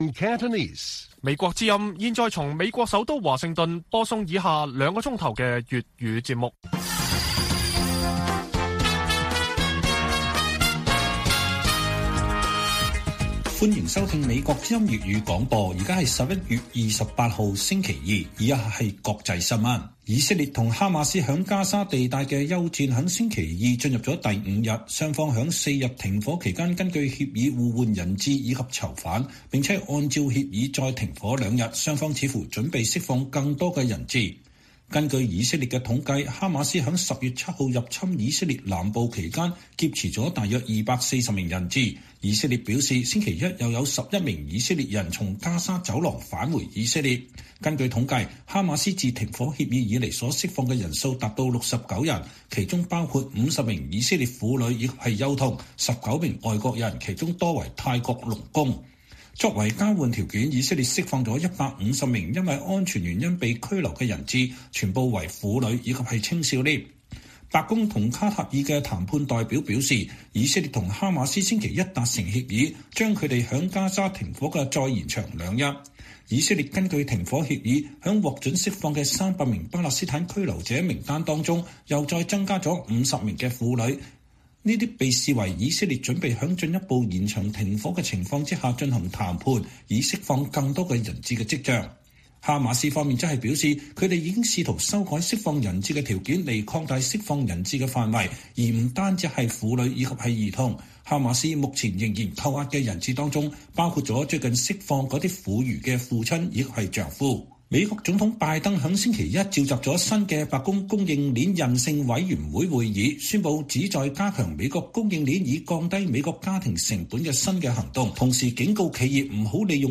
粵語新聞 晚上9-10點: 以色列和哈馬斯維持停火並討論能否再次延長